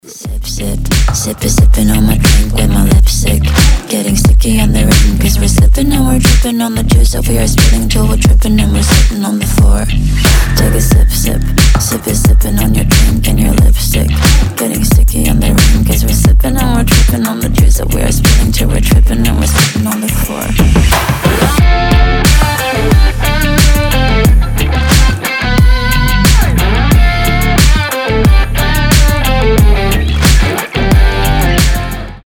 • Качество: 320, Stereo
Midtempo
электрогитара
стильные
приятный женский голос